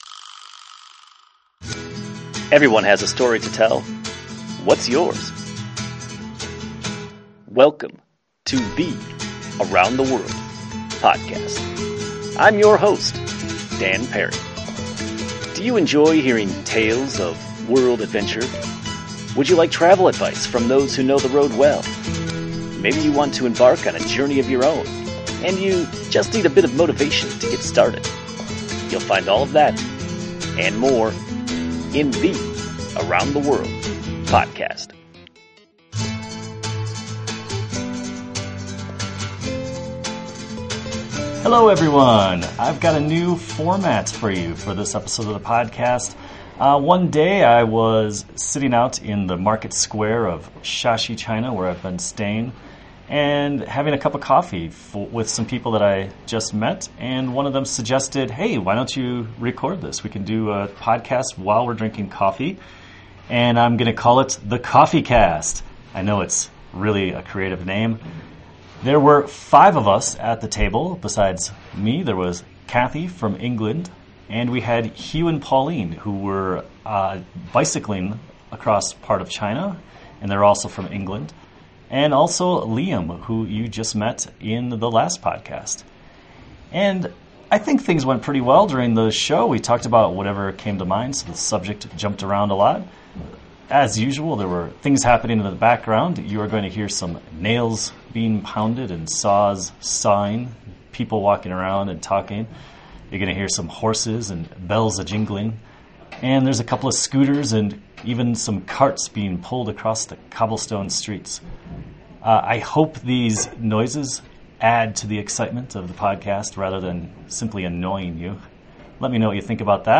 This is our Coffeecast, where we sit around, drink coffee and have a discussion. Recorded in the village of Shaxi, China,
I was sitting in the market square of Shaxi China, drinking coffee and having a chit-chat with four new friends. We decided to record our conversation, which I'll call our “Coffeecast”.